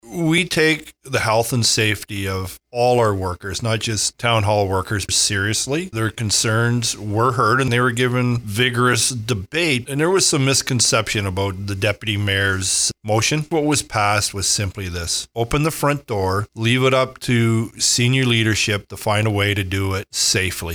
Mayor Colin Grantham says there was some concern as to what the motion was actually asking for.